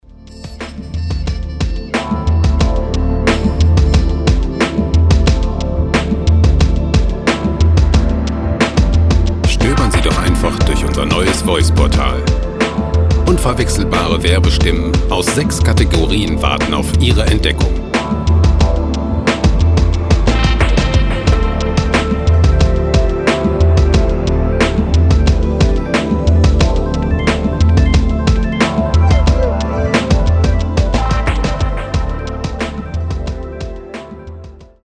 gemafreie Chillout Loops
Musikstil: Chillout
Tempo: 90 bpm